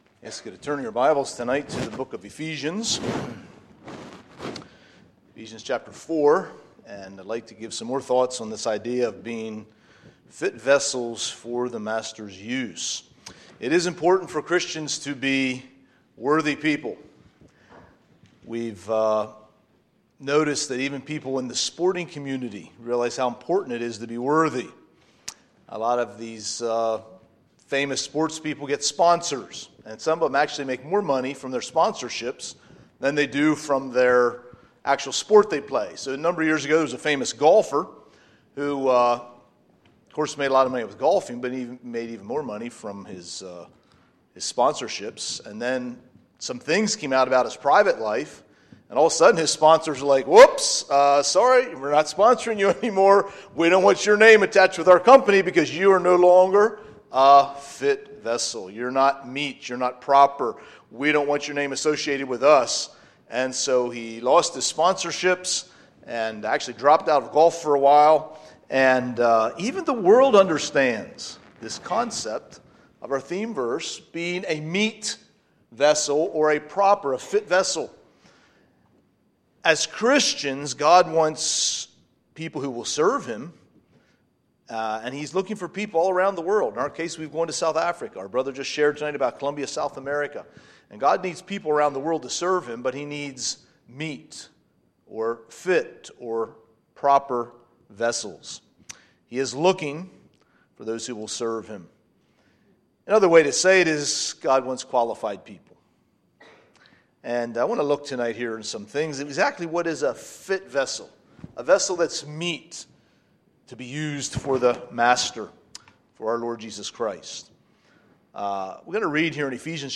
Monday, September 23, 2019 – Missions Conference Session 4